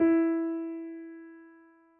piano_052.wav